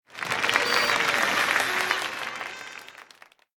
KART_Applause_3.ogg